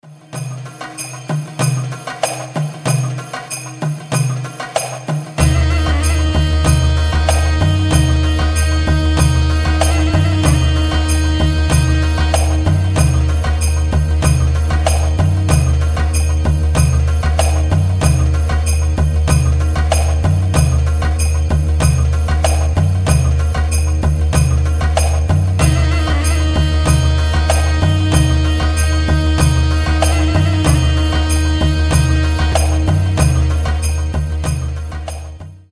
инструментальные
восточные